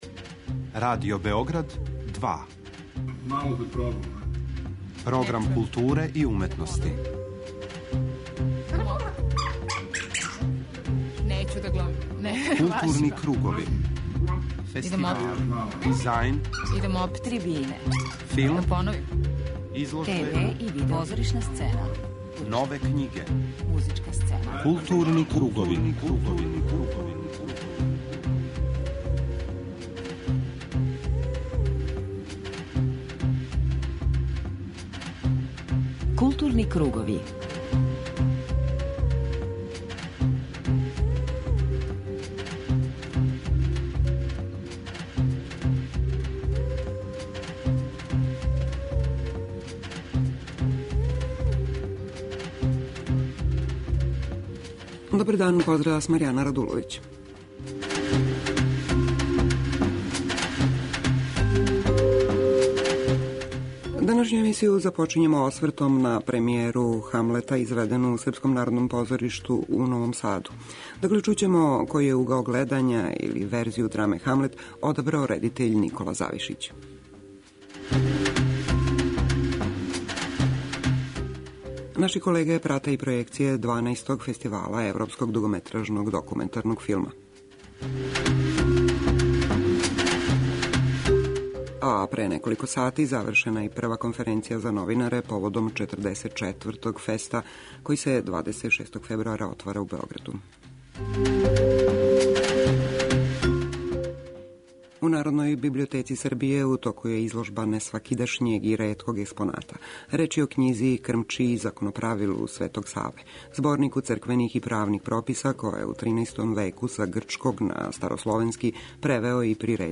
преузми : 41.27 MB Културни кругови Autor: Група аутора Централна културно-уметничка емисија Радио Београда 2.